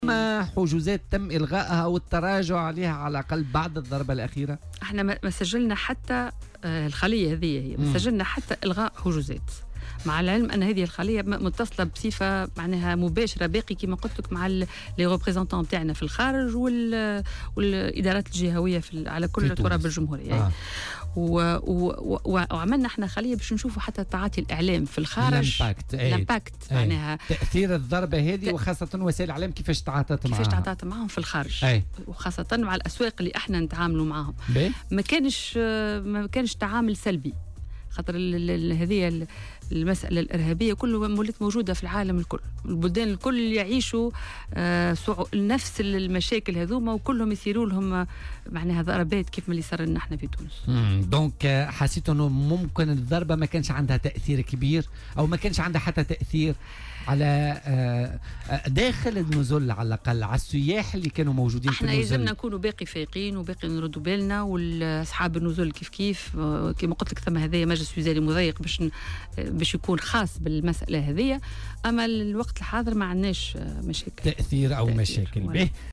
وأضافت اللومي، ضيفة برنامج "بوليتيكا" اليوم الخميس :